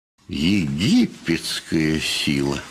Музыкальный момент